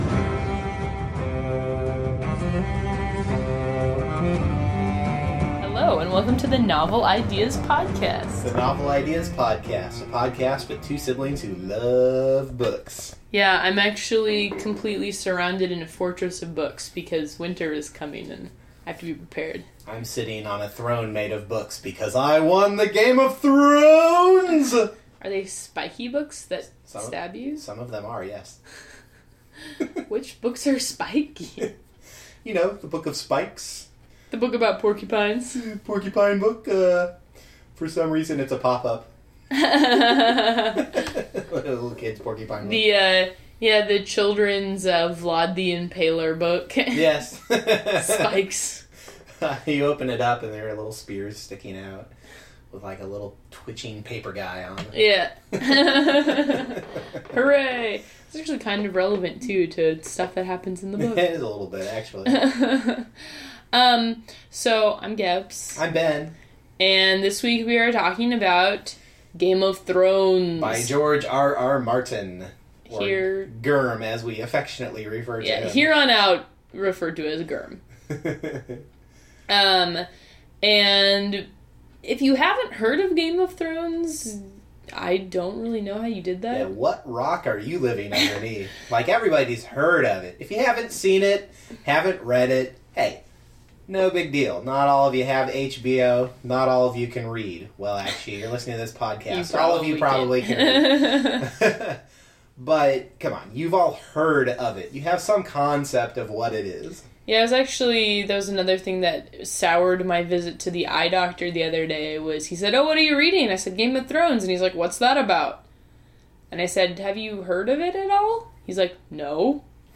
This week on Novel Ideas, a rather exhausted pair of book loving siblings cover Game of Thrones by George RR Martin.
We make some comparisons between the two, though we mainly focus on the book. All in all, it’s a slightly unfocused episode this week as fatigue leads us strange noises, an inability to focus on a topic, and pointless repetition.